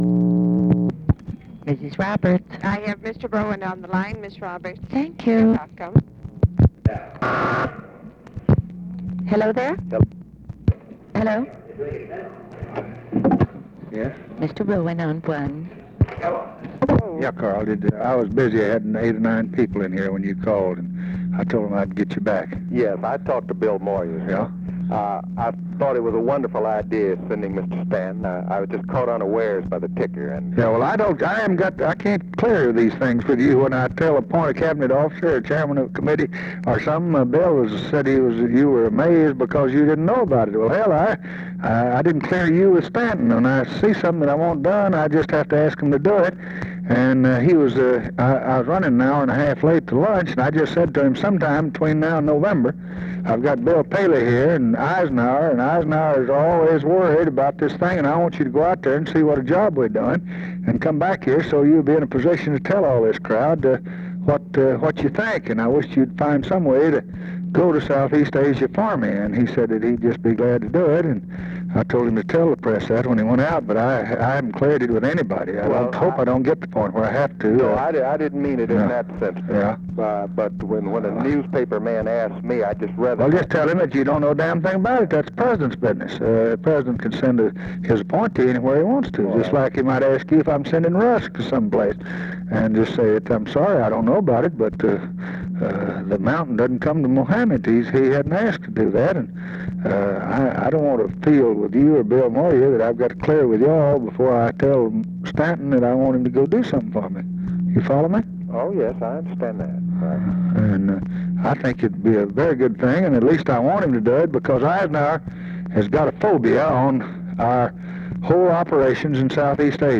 Conversation with CARL ROWAN, July 14, 1964
Secret White House Tapes